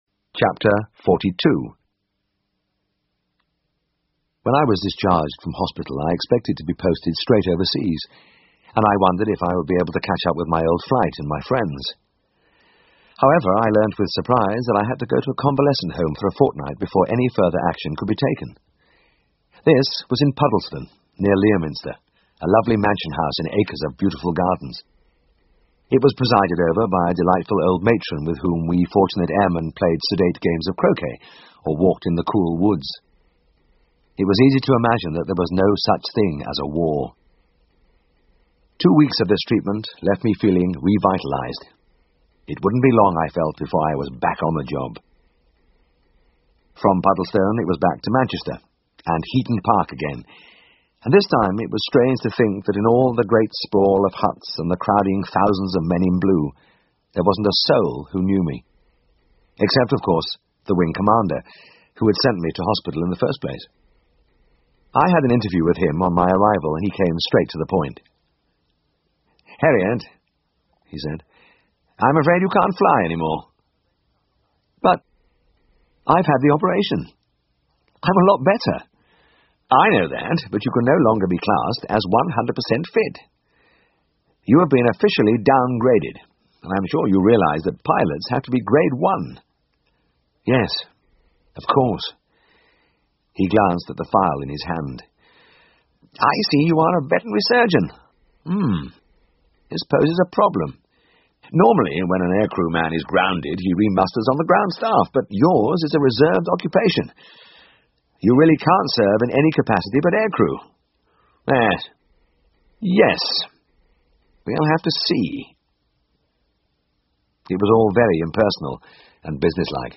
英文广播剧在线听 All Things Wise and Wonderful Chapter 115 听力文件下载—在线英语听力室